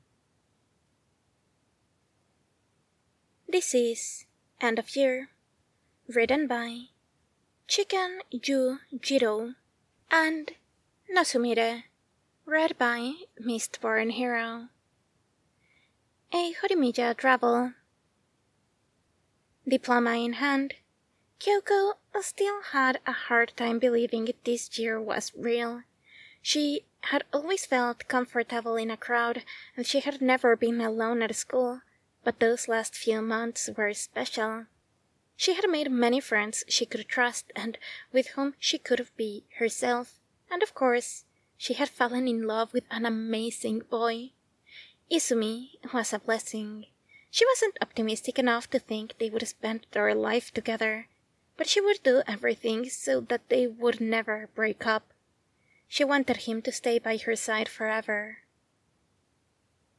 info: collaboration|ensemble